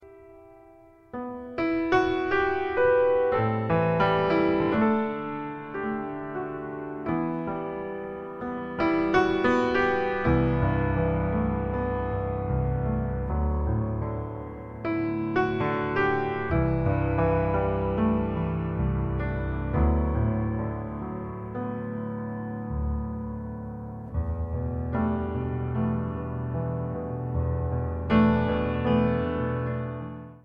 Perfekt zur Entspannung, Gebet und Lobpreis.
• Sachgebiet: Praise & Worship